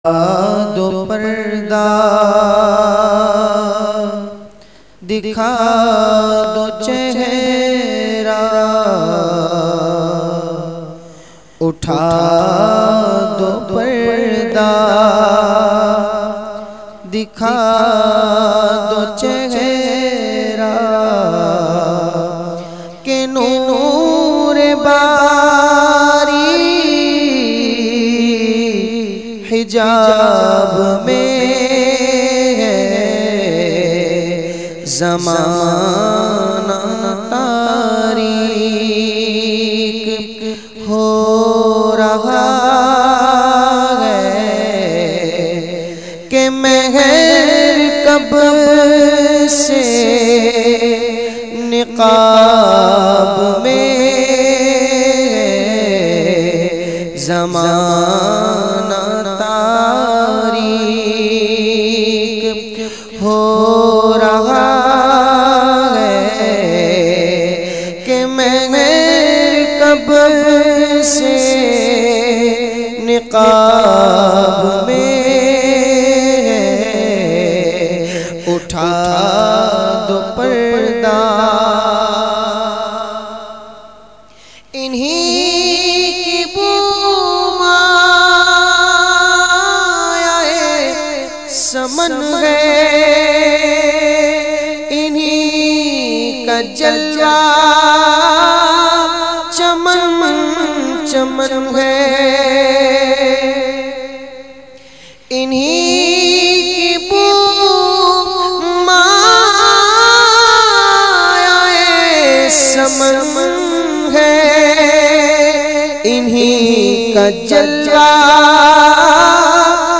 Naat Sharif